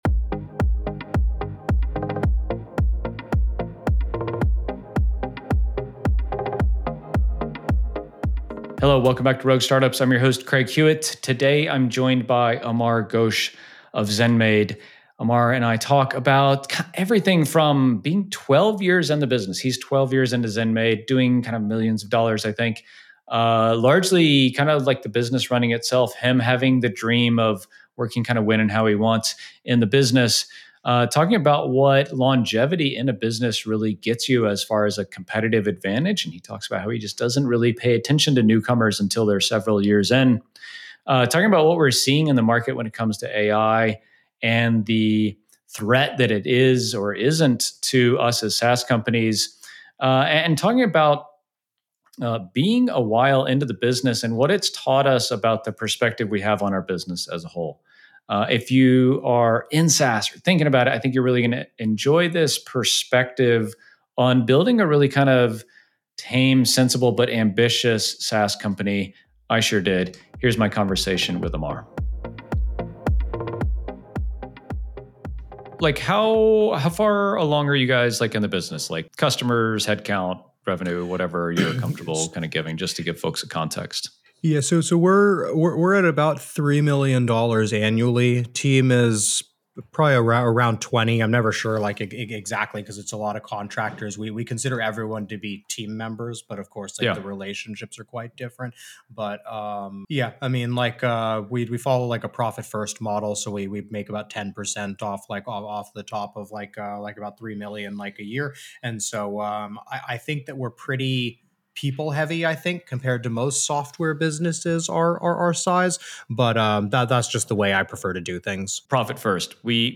In this episode, you will be guided through a deep rest practice that integrates the pathways of the Kabbalistic energy centers (the sefirot).